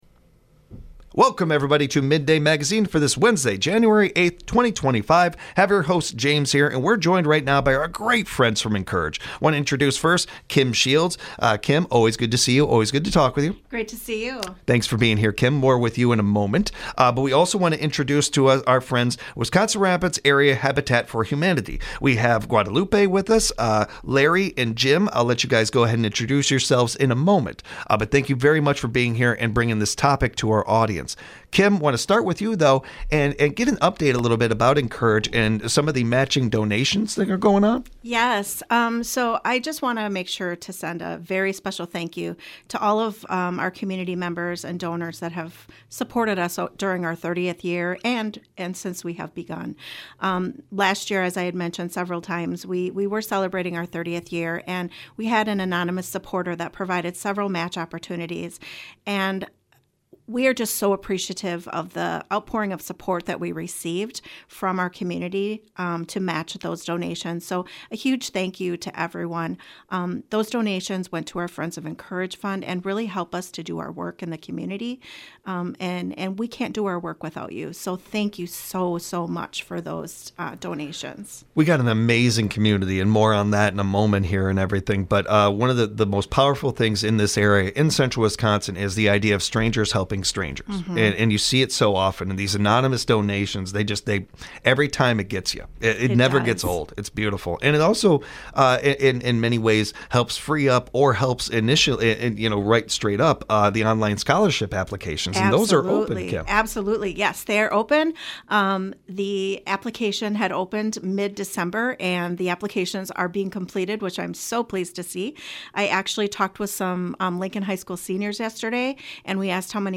Mid-day Magazine gives you a first look into what’s happening in the Central Wisconsin area. WFHR has a variety of guests such as non-profit organizations, local officials, state representatives, event coordinators, and entrepreneurs.